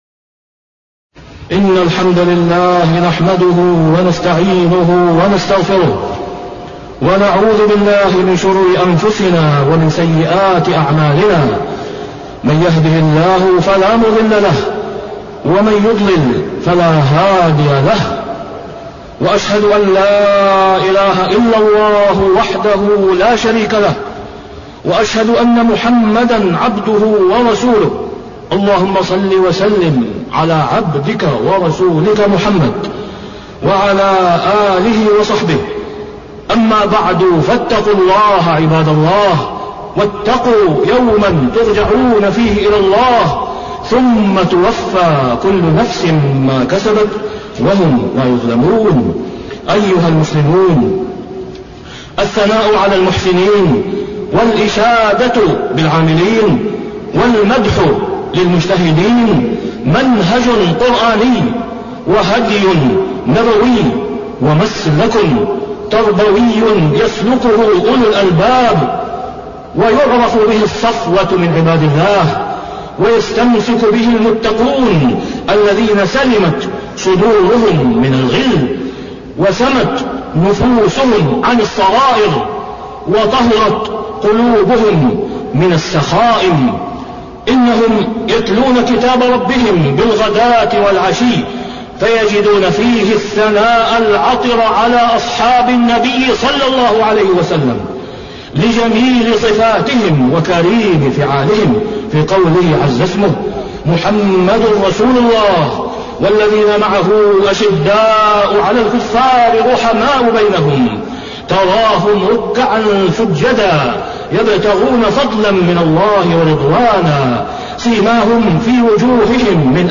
تاريخ النشر ٩ جمادى الآخرة ١٤٢٩ هـ المكان: المسجد الحرام الشيخ: فضيلة الشيخ د. أسامة بن عبدالله خياط فضيلة الشيخ د. أسامة بن عبدالله خياط الثناء على المحسنين